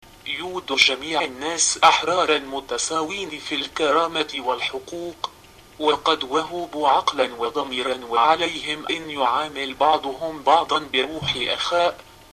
Text to speech
I thought the Arabic sounded fairly good, altho there was a kind of robotic or computer like rythym. I could detect as tho it sounded like words read in isolation then strung together to make this sentence.
The Arabic does indeed sound stilted but the pronunciation is good (although the intonation is bad).